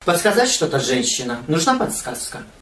Категория: голосовые